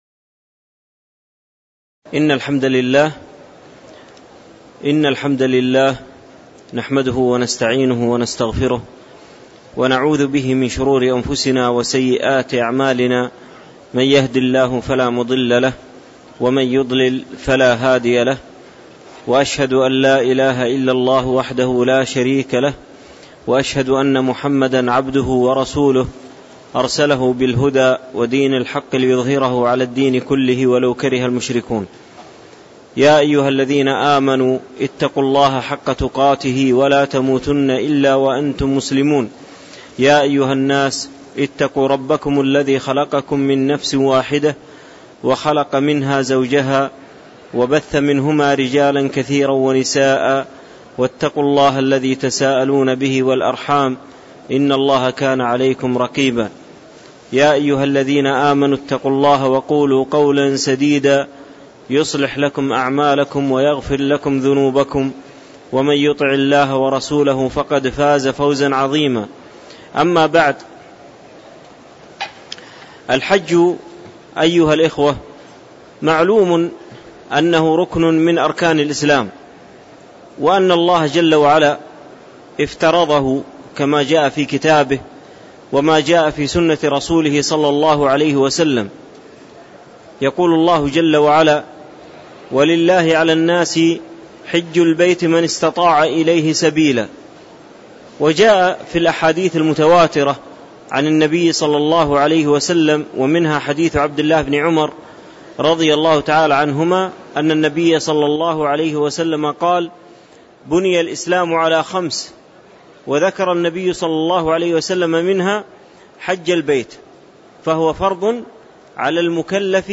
تاريخ النشر ١٨ ذو القعدة ١٤٣٧ هـ المكان: المسجد النبوي الشيخ